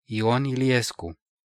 Ion Iliescu (Romanian pronunciation: [iˈon iliˈesku]
Ro-Ion_Iliescu.ogg.mp3